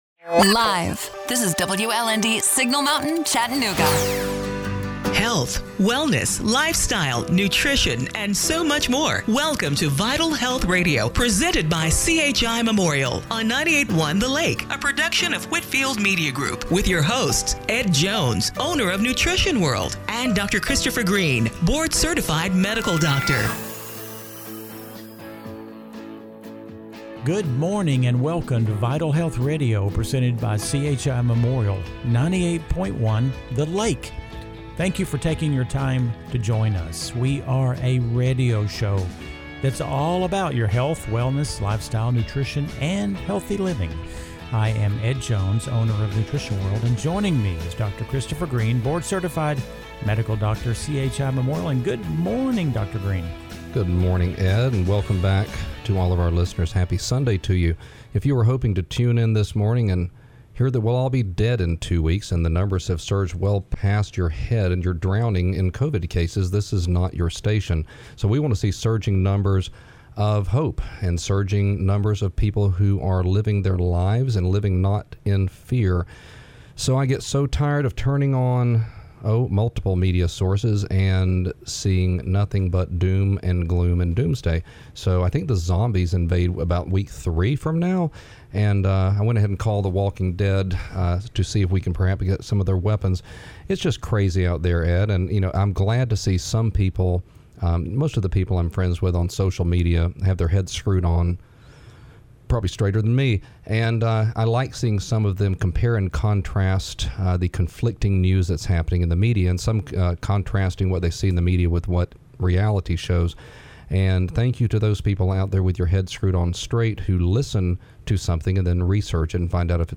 June 28, 2020 – Radio Show - Vital Health Radio